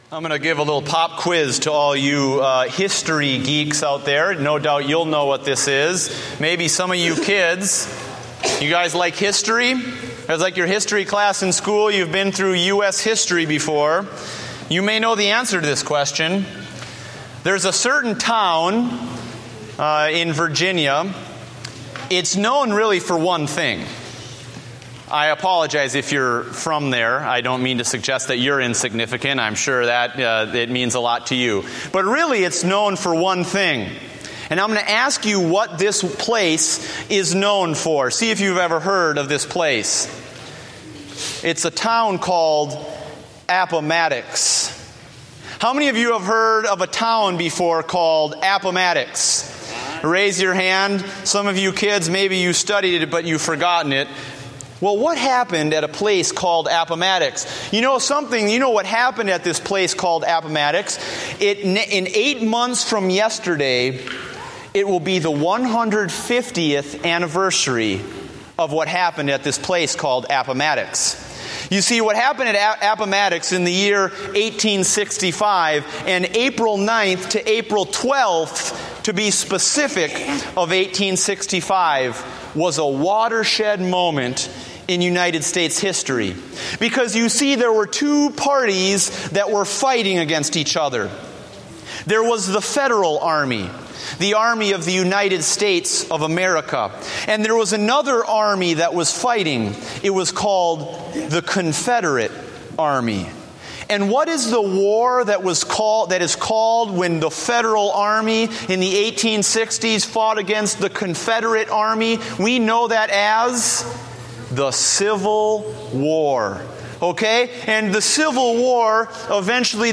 Date: August 10, 2014 (Morning Service)